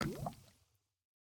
drip_lava_cauldron2.ogg